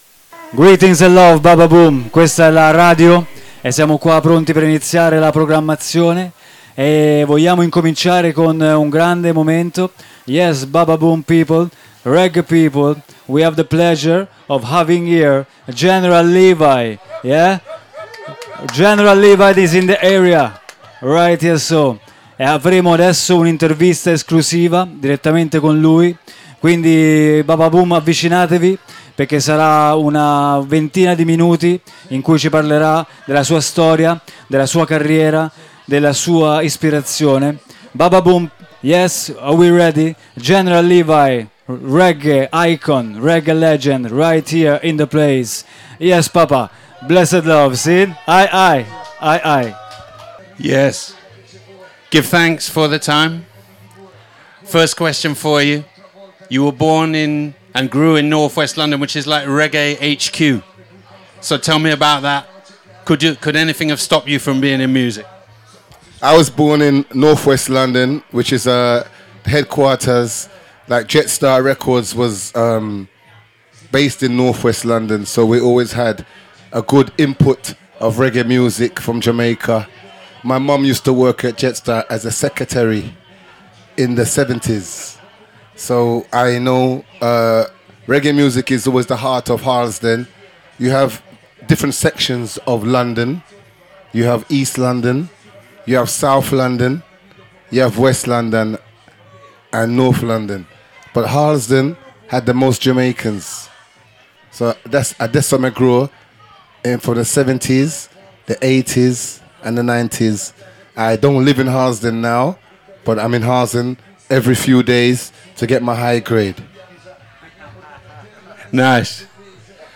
🎙 General Levy – L’energia jungle incontra la spiaggia del Bababoom 📍 Intervista esclusiva dal Bababoom Festival 2025 · Marina Palmense · Radio Città Aperta
Registrata direttamente dalla Beach Yard del Bababoom, l’intervista è accompagnata da selezioni musicali in levare , vibrazioni positive e il rumore del mare in sottofondo. 🌊 Una conversazione intensa e ritmata, tra flow, memoria e visione , per raccontare la musica come strumento di libertà e connessione.